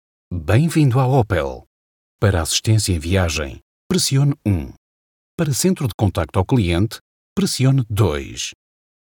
Natuurlijk, Speels, Vriendelijk, Warm, Zakelijk
Telefonie